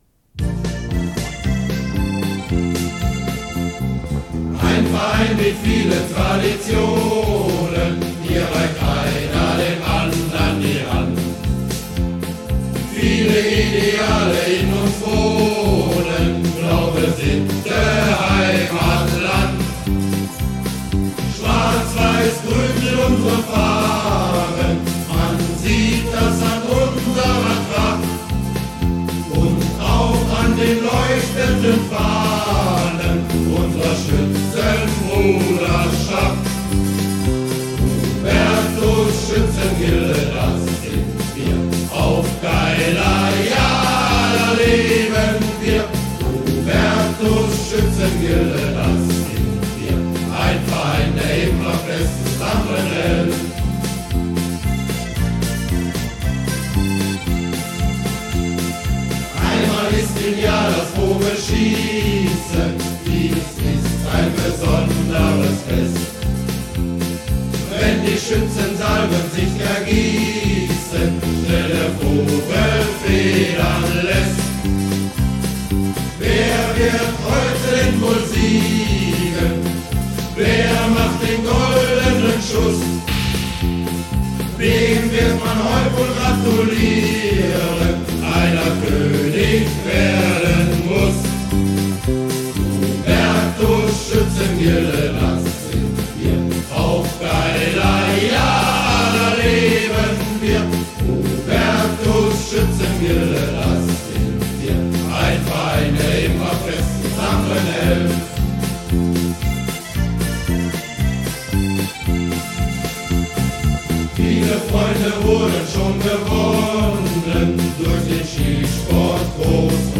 von Hubertuschor 1992
Lied-der-Hubertus-Schuetzengilde-1992-gesungen-vom-Hubertuschor.mp3